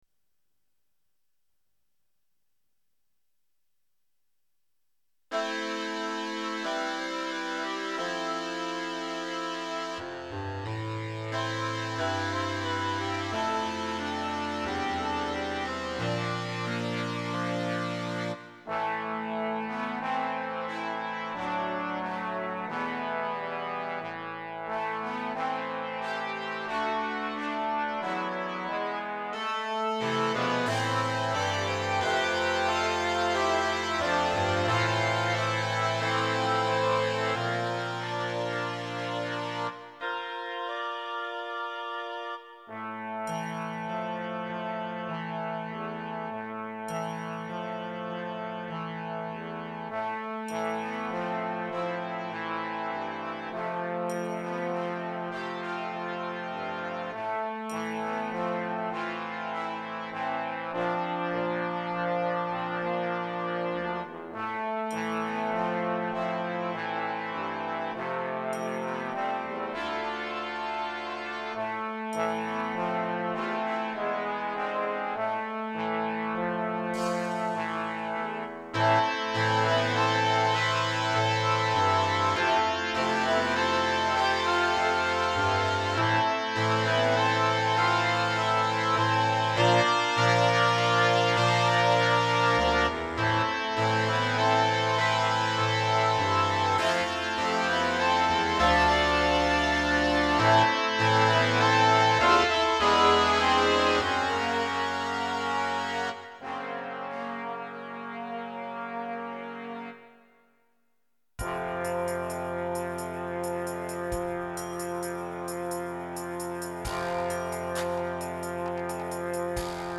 Style: Ballad
Instrumentation: Standard Concert Band